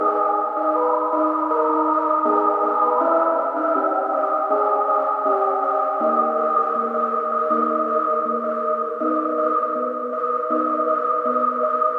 Tag: 80 bpm Electro Loops Synth Loops 2.02 MB wav Key : Unknown